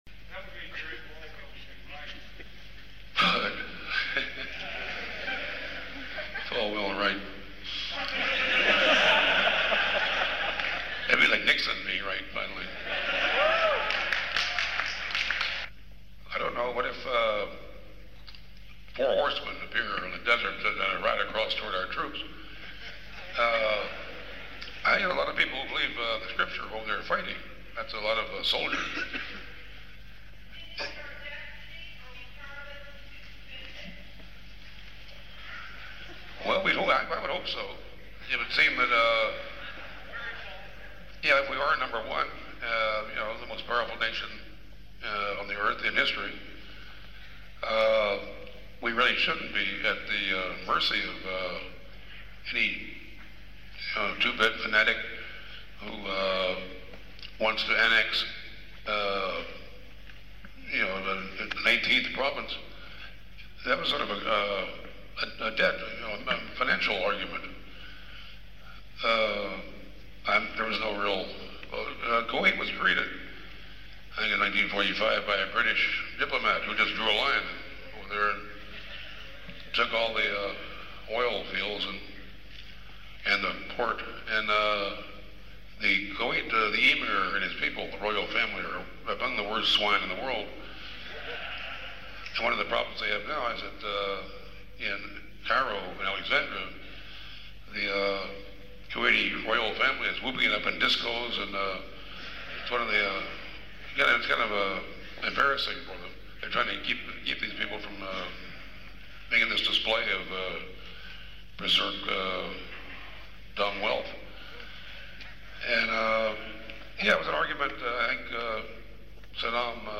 Live audio of Hunter S. Thompson at Washington & Lee University on April 4th 1991.